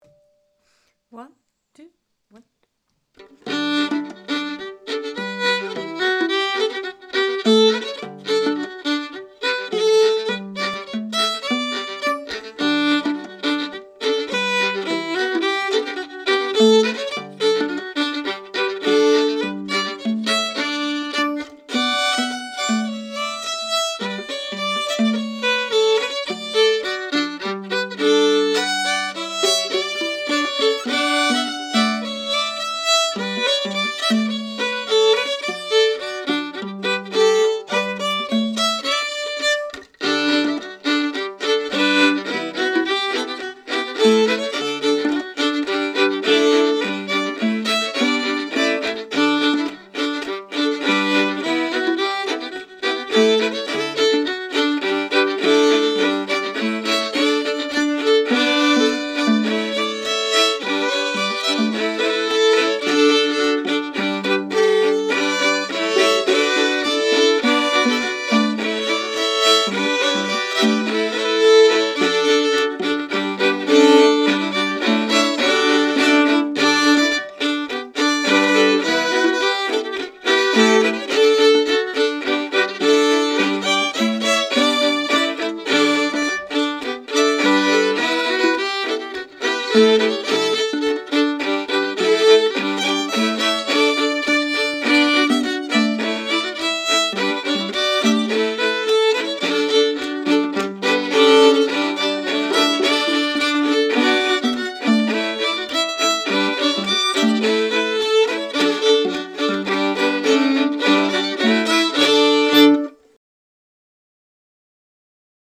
Key: D
Form: Reel
Source: Trad.
Genre/Style: Morris dance reel